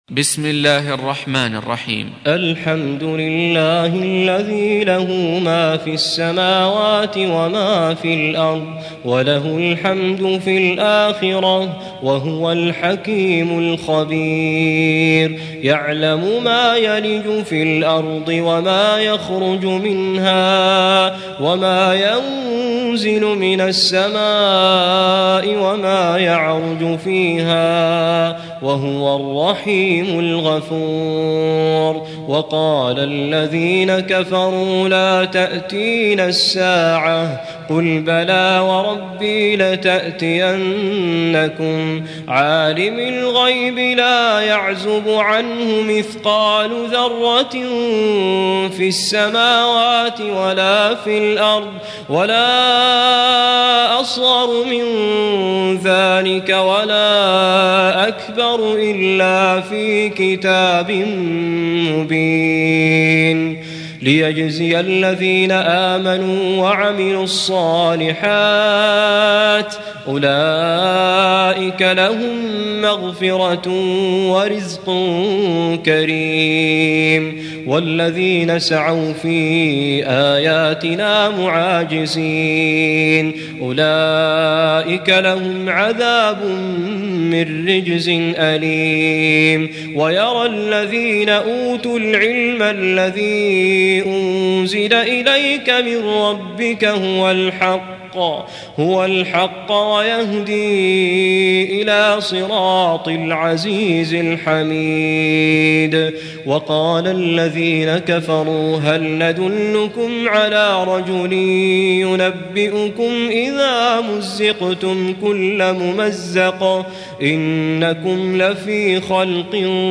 Surah Repeating تكرار السورة Download Surah حمّل السورة Reciting Murattalah Audio for 34. Surah Saba' سورة سبأ N.B *Surah Includes Al-Basmalah Reciters Sequents تتابع التلاوات Reciters Repeats تكرار التلاوات